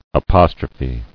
[a·pos·tro·phe]